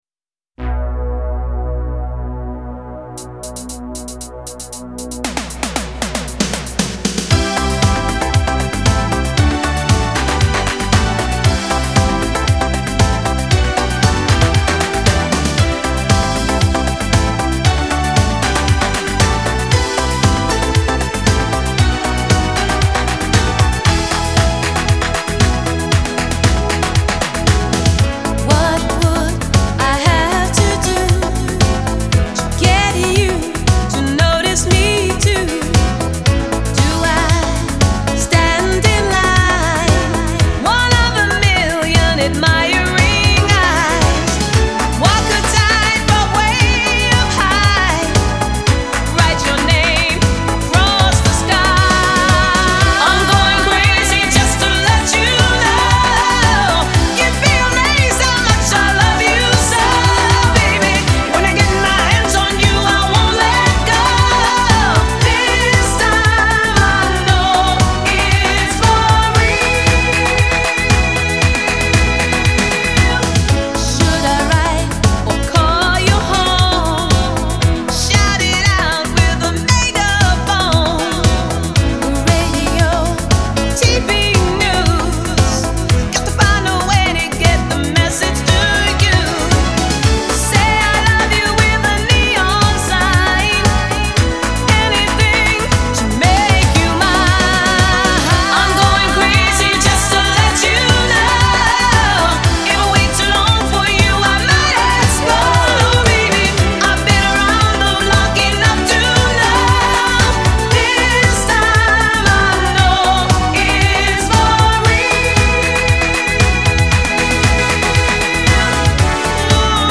Genre : Disco